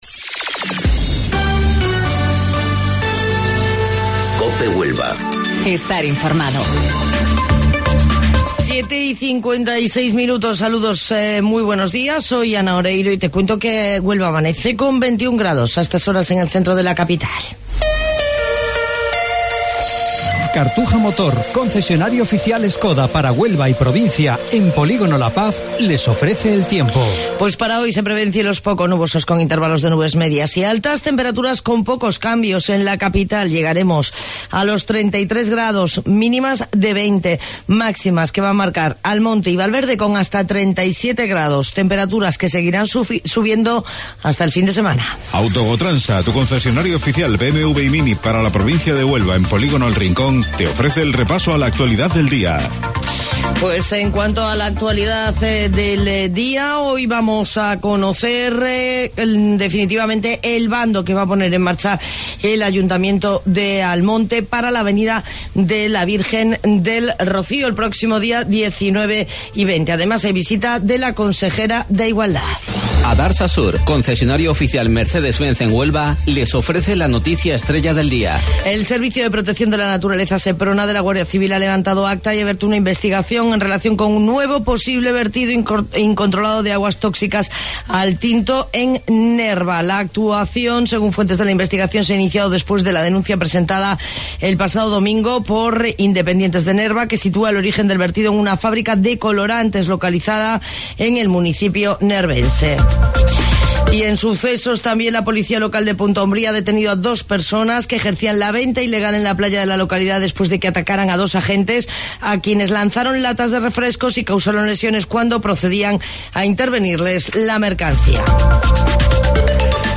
AUDIO: Informativo Local 07:55 del 7 de Agosto